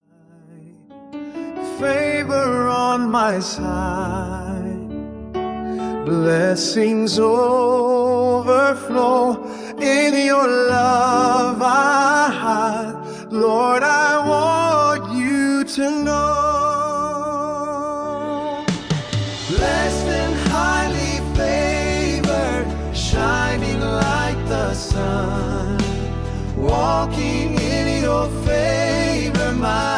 Ai generated